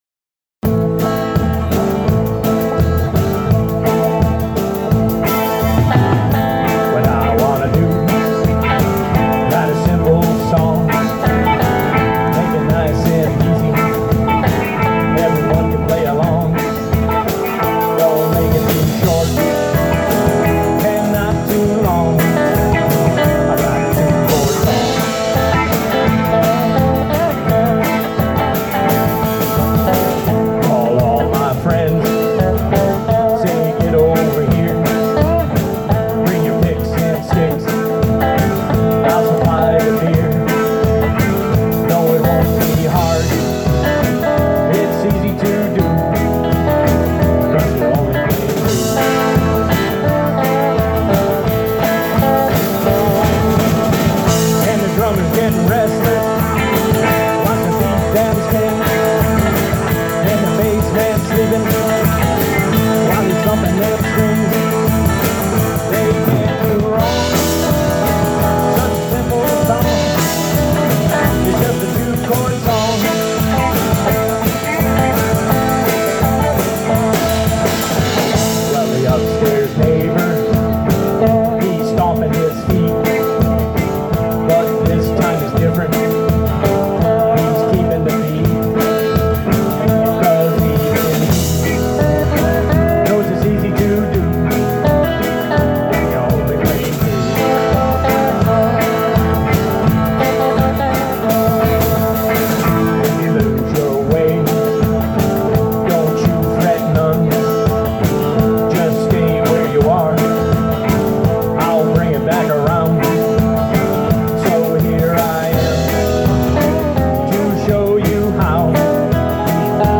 They are not mixed properly. Sounds pretty good though! Most songs need adjusting and tweaking.
This event was recorded at the North Stonington Middle School-High School grounds in North Stonington, CT. using an Android Galaxy 7 Edge and a SoundCraft Ui24R on September 22nd, 12:00PM-4:00PM.
country rock band
lead vox and acoustic guitar
upright bass
electric guitar
drums